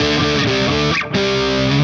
AM_HeroGuitar_130-D01.wav